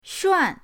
shuan4.mp3